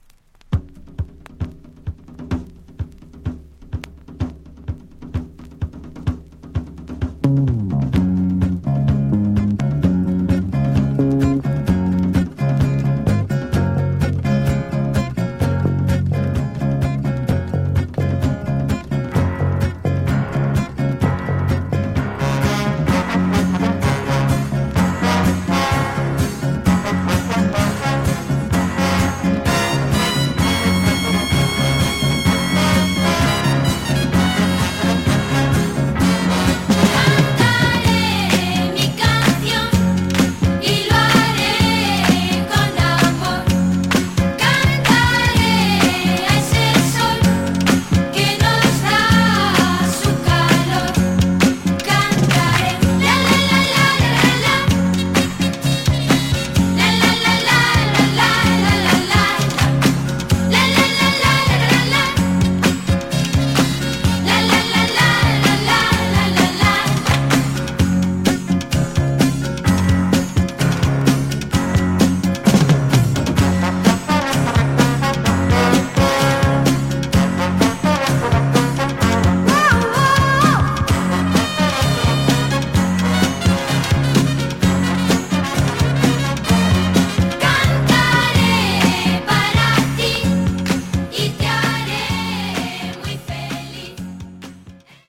disk : VG+ to EX- （多少チリプチでますが、試聴曲は良好です。）